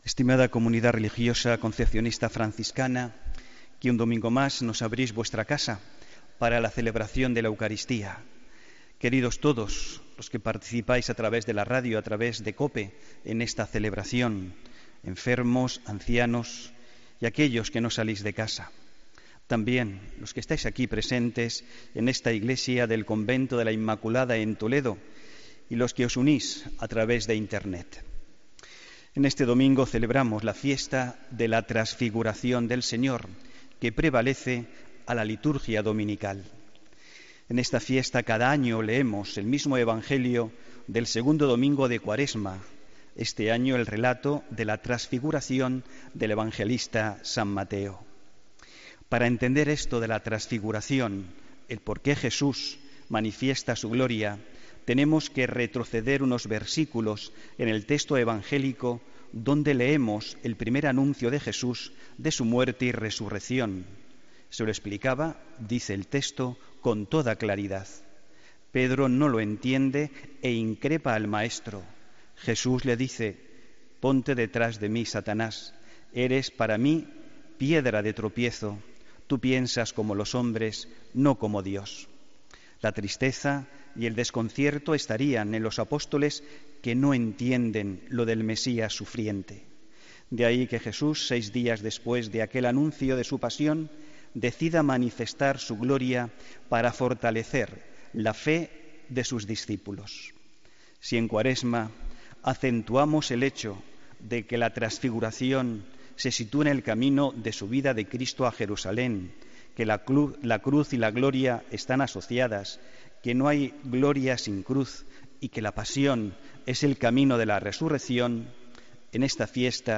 Homilía del domingo 6 de agosto de 2017